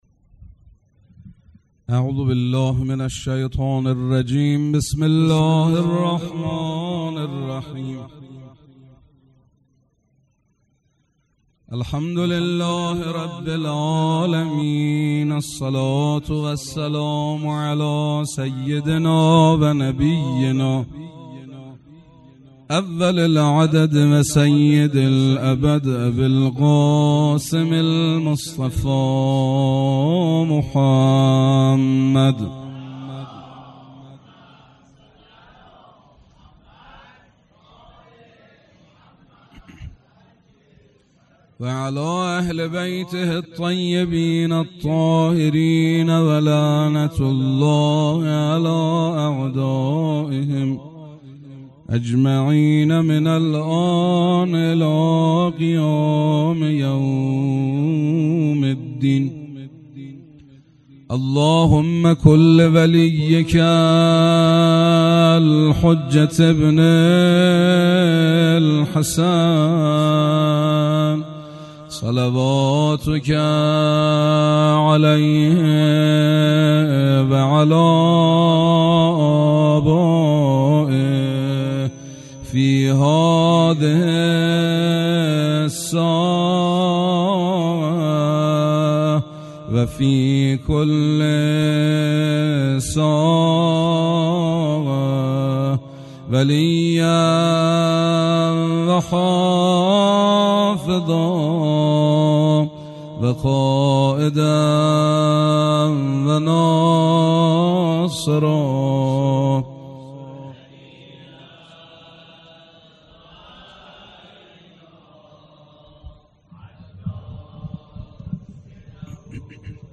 مناجات
شب دوم برنامه عیدسعیدغدیر